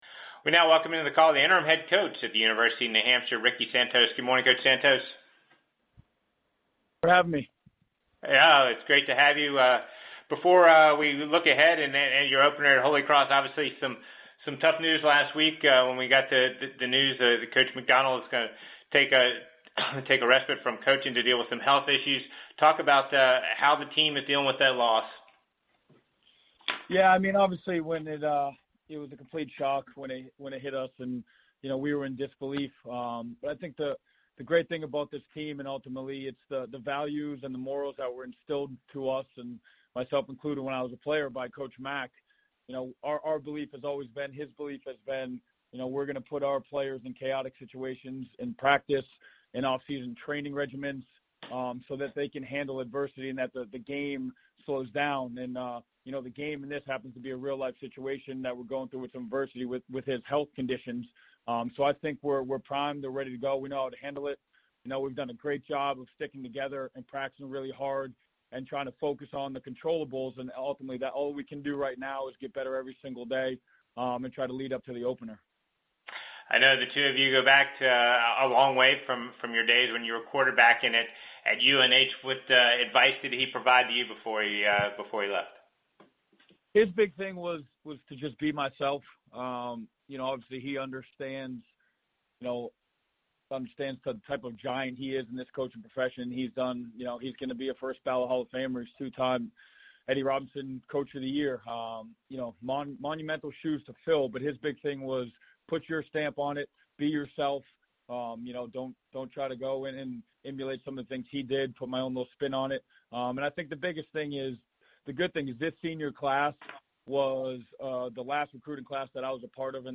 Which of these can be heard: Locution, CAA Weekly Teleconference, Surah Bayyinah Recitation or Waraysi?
CAA Weekly Teleconference